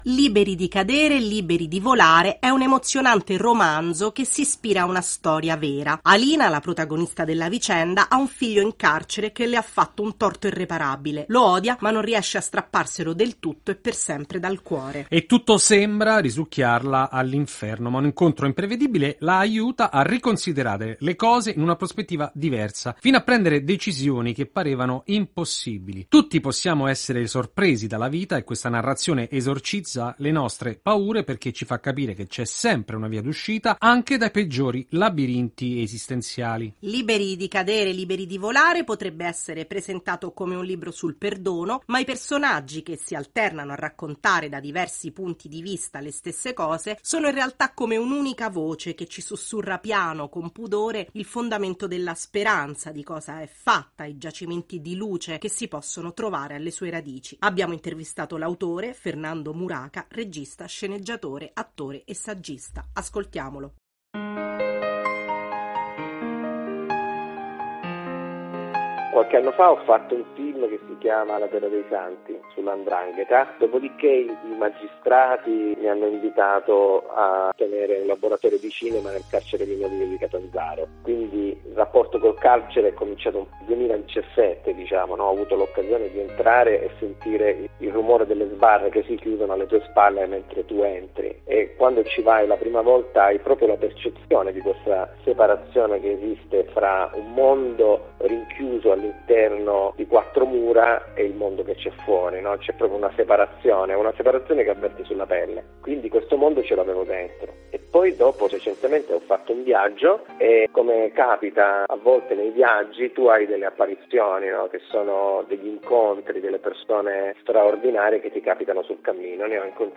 Riportiamo l’intervista all’autore del libro